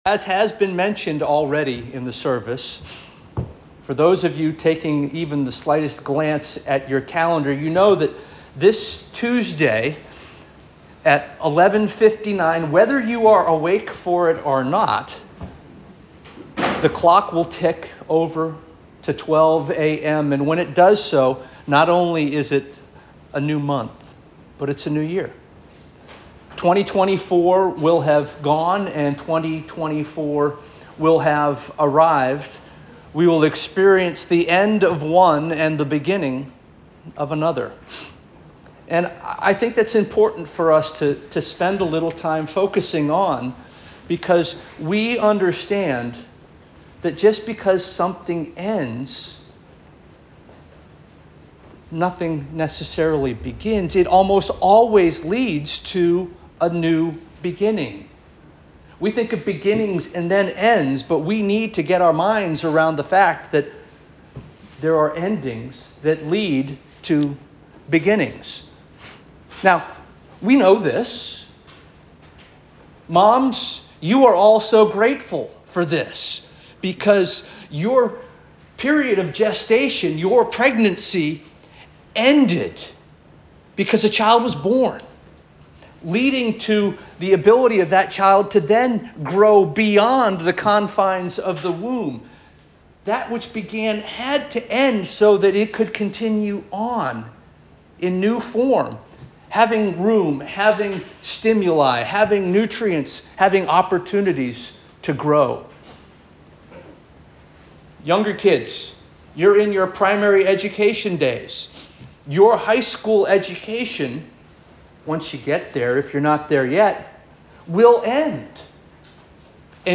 December 29 2024 Sermon